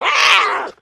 sounds / monsters / cat / hit_4.ogg
hit_4.ogg